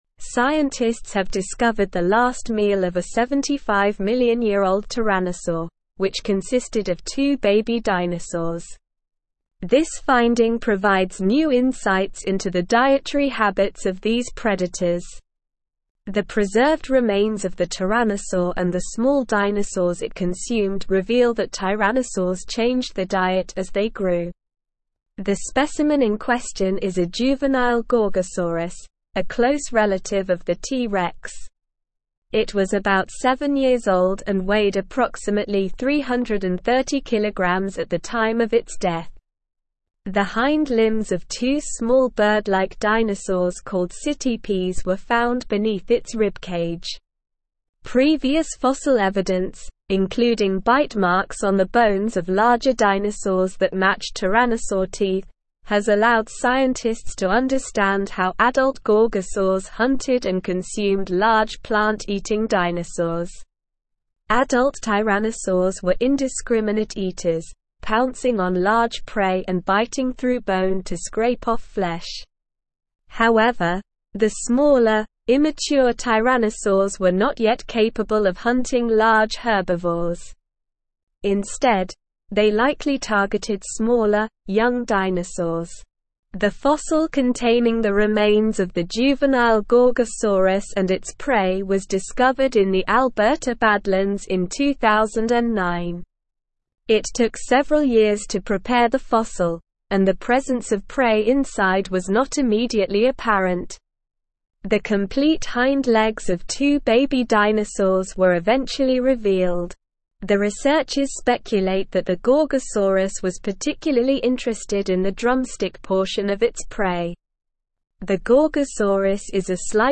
Slow
English-Newsroom-Advanced-SLOW-Reading-Ancient-Tyrannosaurs-Last-Meal-Two-Baby-Dinosaurs.mp3